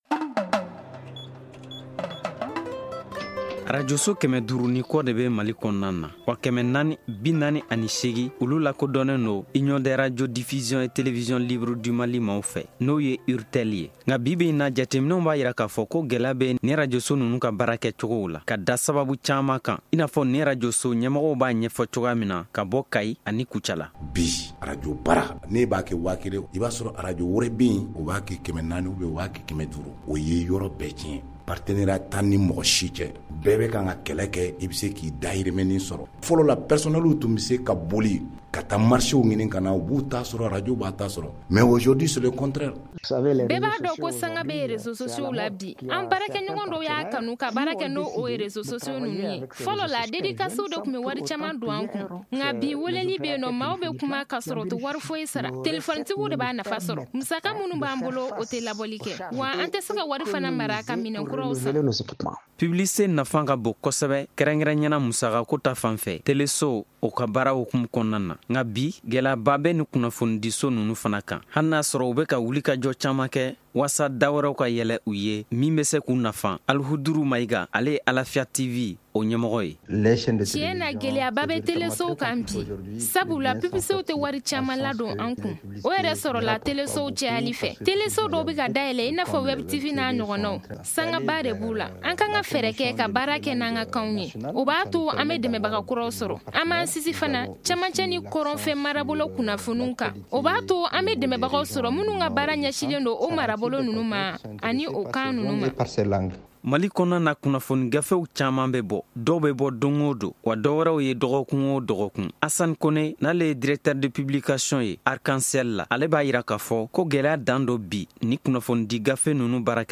Le magazine économie de Studio Tamani s’intéresse au sujet